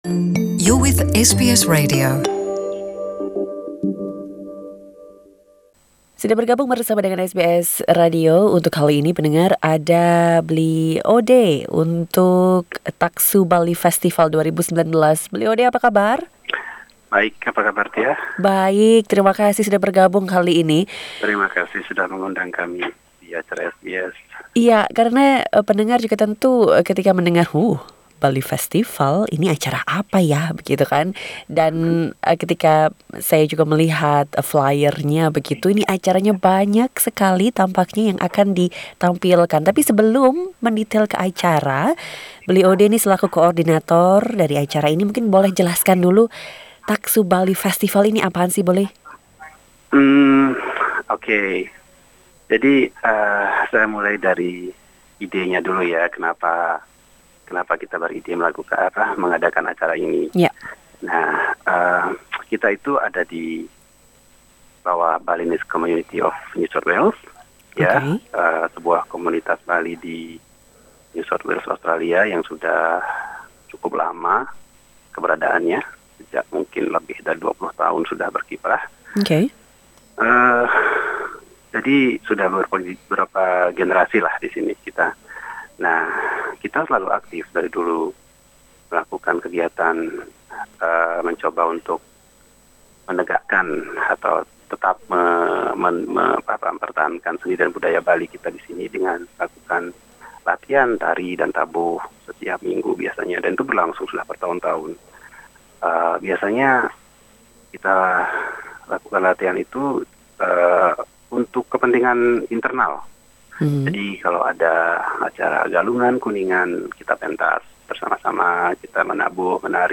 Source: Sanggar Tari Bali Saraswati Sydney Dengarkan podcast untuk wawancara selengkapnya.